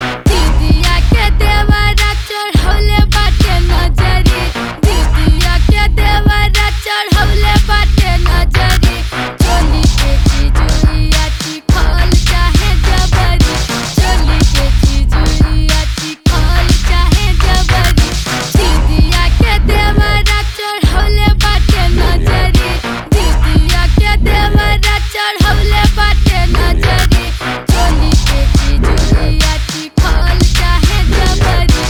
Party Song